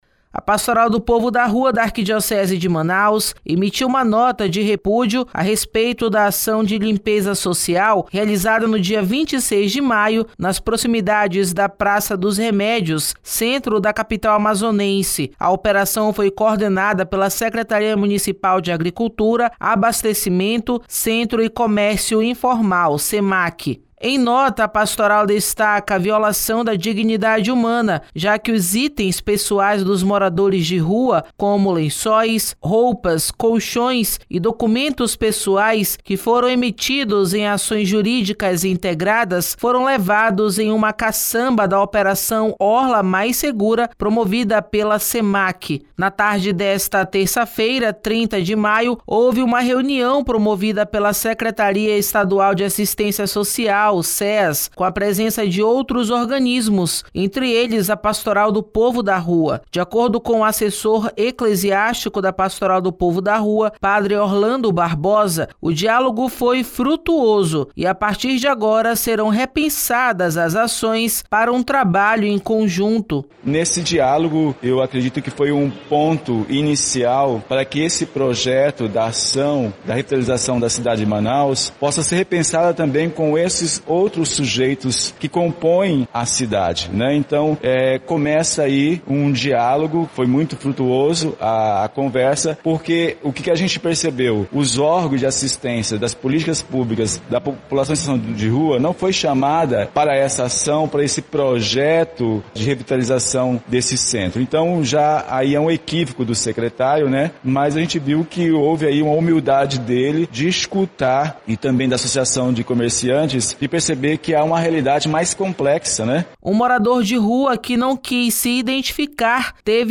Um morador de rua, que não quis se identificar, teve o colchão, roupas e os documentos levados na ação.